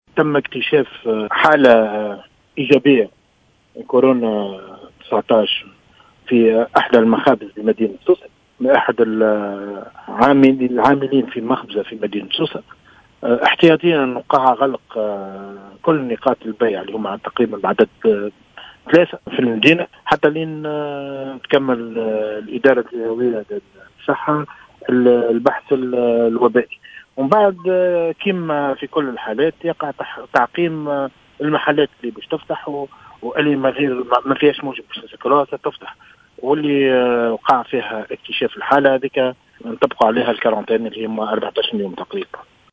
من جهته أكدّ رئيس بلدية سوسة محمد إقبال خالد في تصريح للجوهرة أف أم، أنّه تمّ اتخاذ قرار غلق كل المحلات احتياطيا، إلى حين انتهاء البحث الوبائي، ثم القيام بعملية التعقيم اللازمة لجميع المحلات، مشيرا إلى أنّ الإصابة، كانت نتيجة عدوى أفقية.